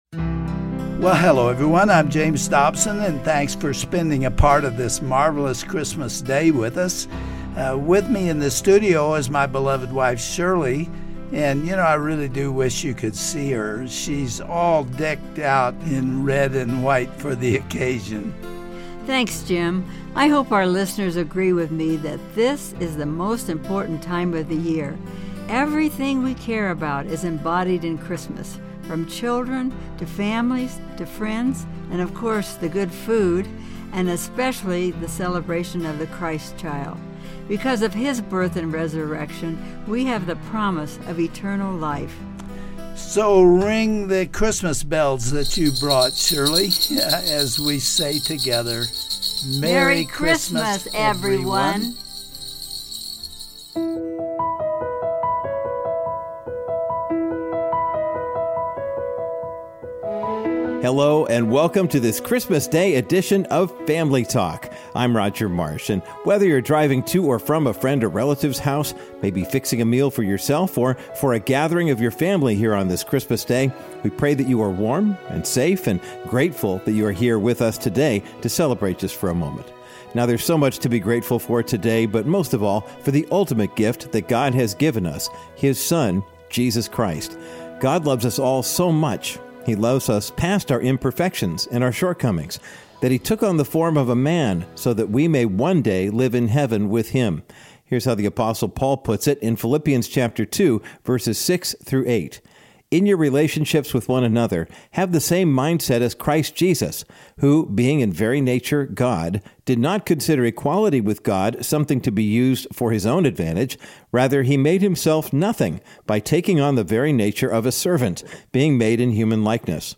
Seven days before Christmas, on a cold, snowy late afternoon, a widow named Stella received a surprise delivery. On today's edition of Family Talk, Dr. James Dobson reads a story from his book entitled A Family Christmas. Snuggle up with a cozy blanket and listen to this heartwarming tale about love, loss, and hope, read by the familiar, comforting voice of Family Talk's host and founder.